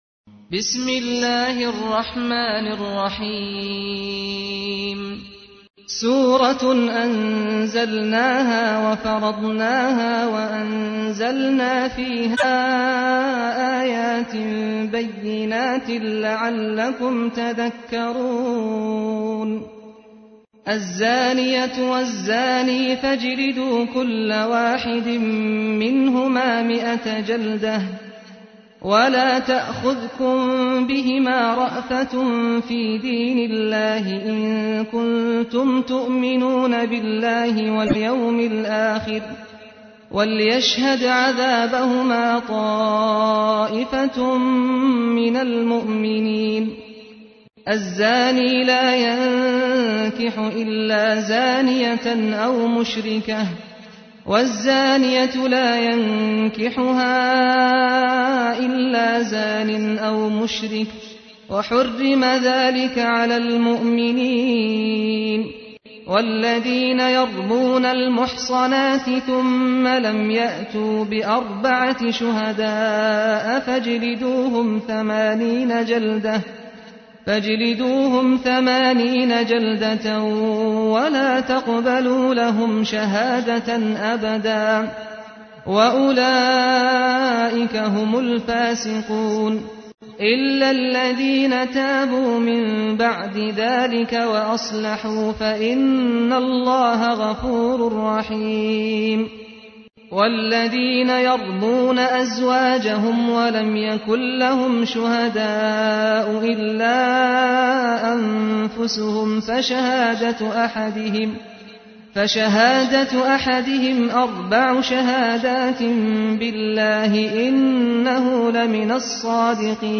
تحميل : 24. سورة النور / القارئ سعد الغامدي / القرآن الكريم / موقع يا حسين